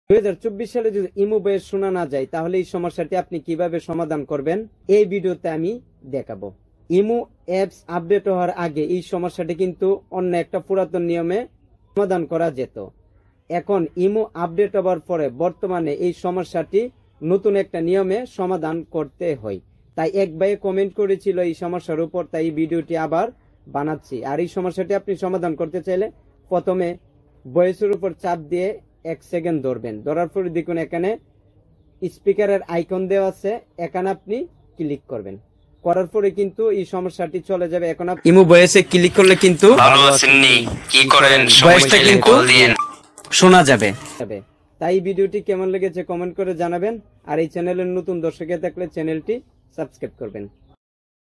Imo Switched To Ear Speaker sound effects free download